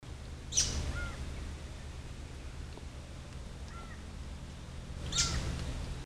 Scientific name: Syndactyla rufosuperciliata acrita
English Name: Buff-browed Foliage-gleaner
Condition: Wild
Certainty: Recorded vocal